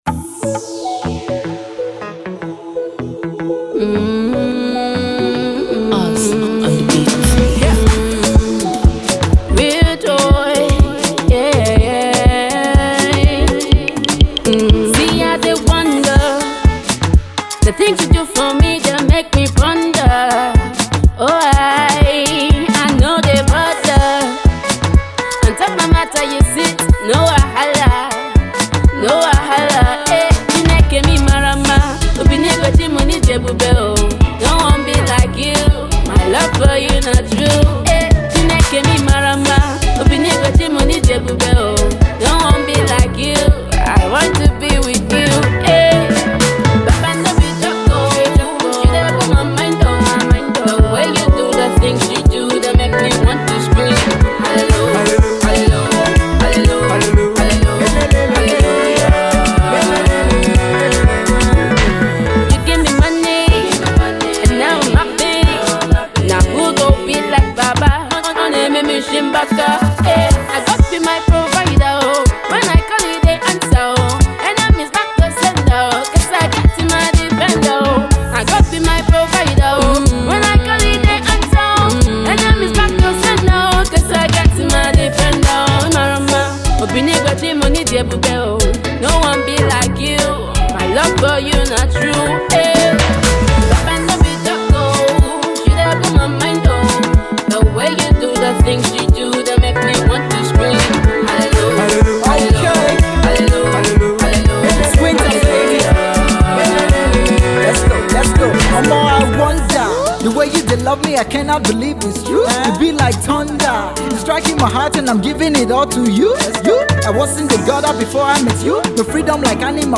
Gospel …